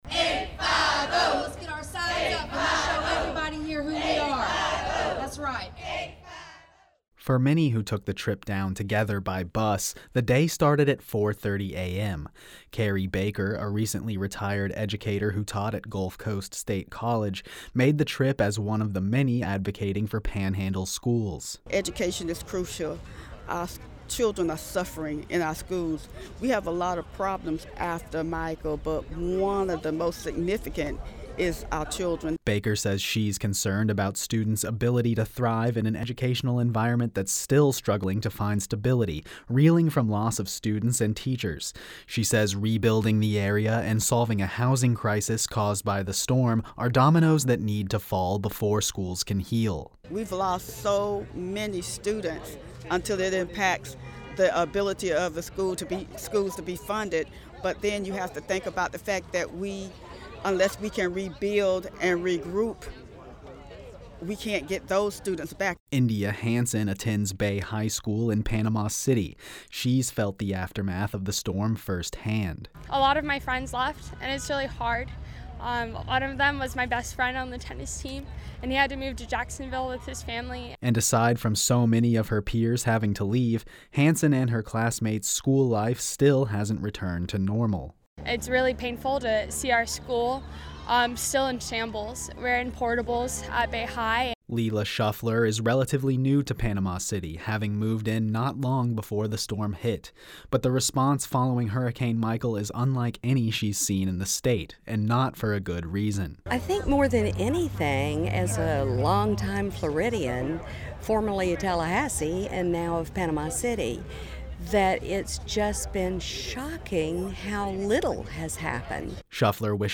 Florida CFO Jimmy Patronis speaks to a crowd of Panhandle residents on the steps of the state Historic Capitol Thursday.